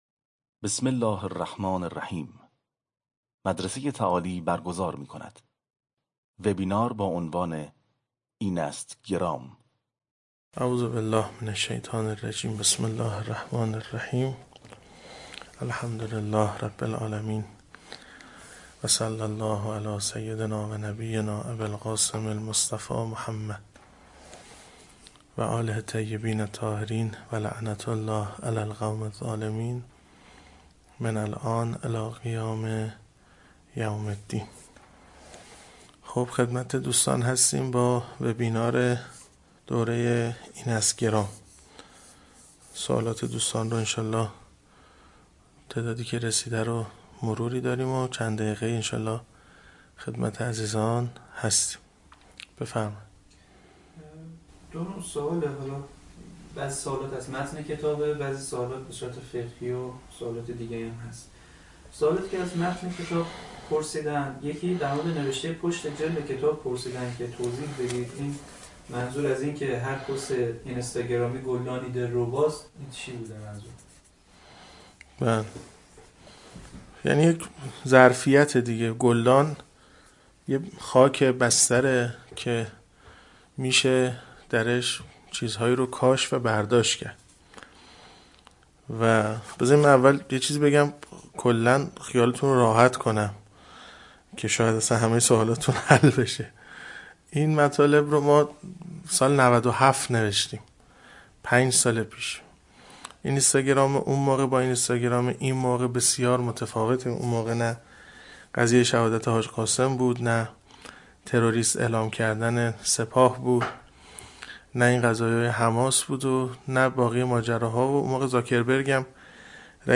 جلسه پرسش و پاسخ